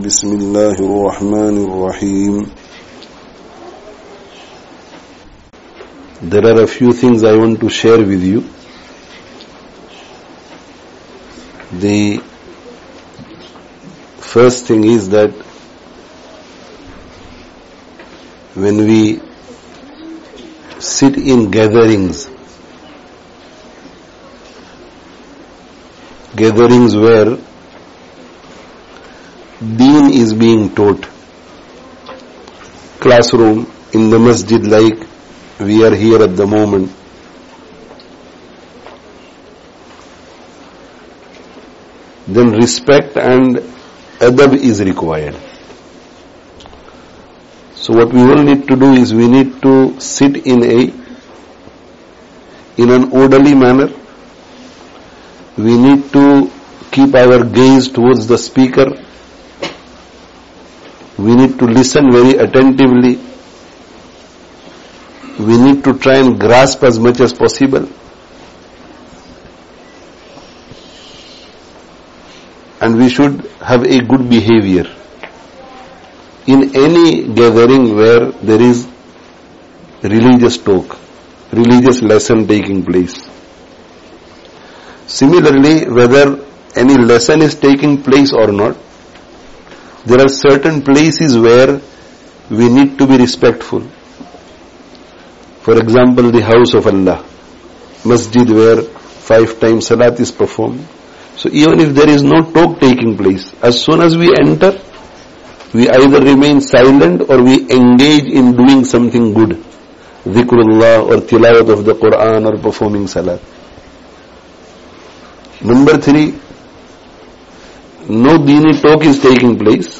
Adab, Ilm and Dhikr: A Short Talk Delivered to Primary Level Madrasah Students [Annual Jalsah] (Al-Madrasatul Madaniyyah, St. Matthews, Leicester 09/08/18)